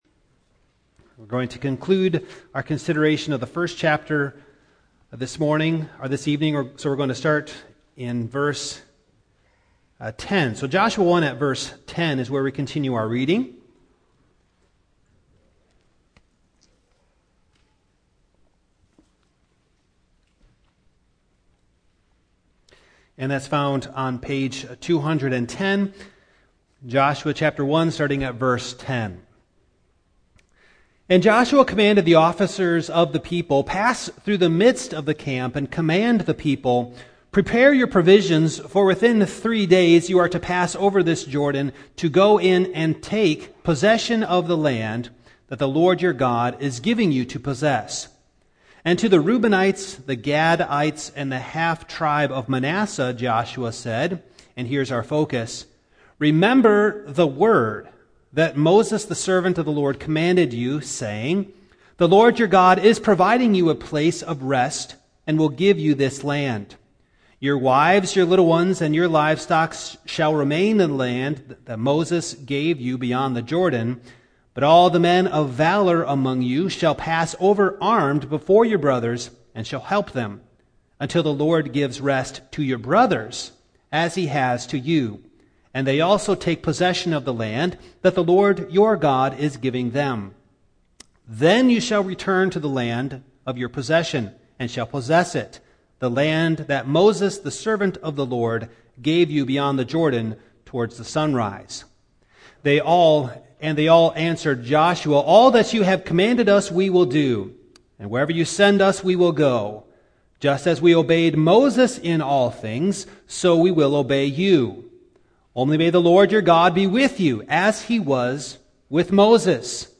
The Book of Joshua Passage: Joshua 1:10-18 Service Type: Evening Download Files Notes « The Providence of God Who do You say that He Is?